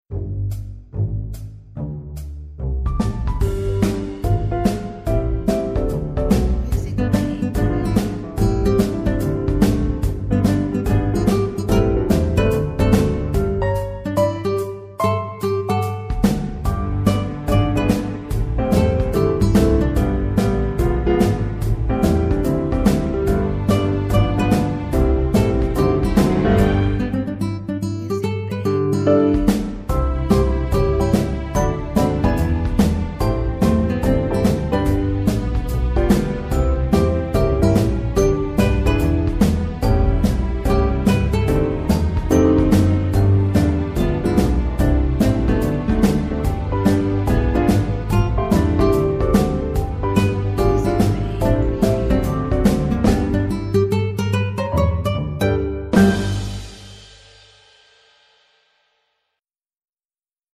Music instrumentals for film.